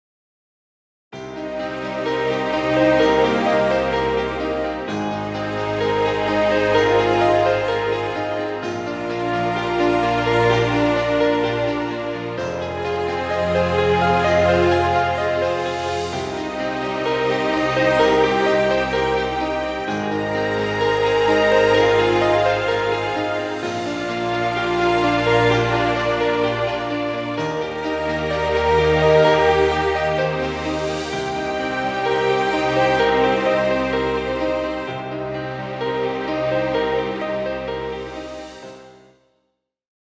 Background Music Royalty Free.